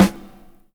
Snare Steveland 1.wav